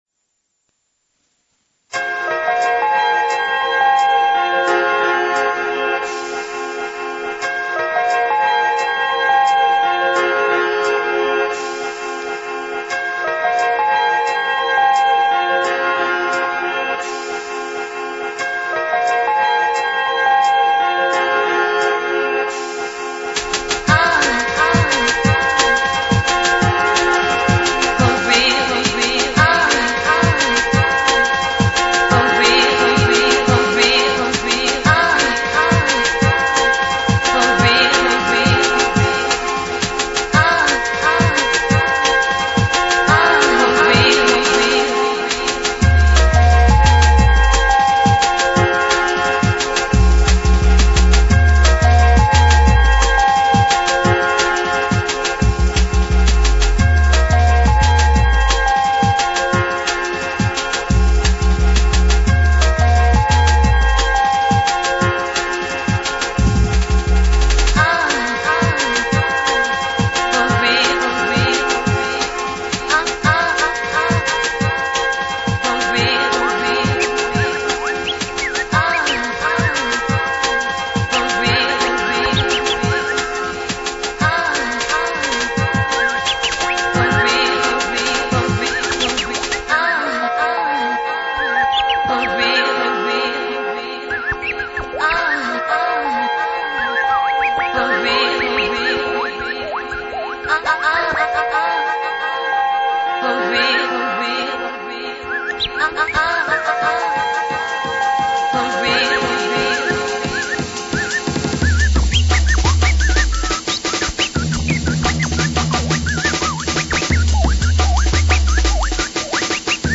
old skool dnb/jungle mix (fast n' furious)